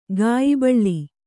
♪ gāyibaḷḷi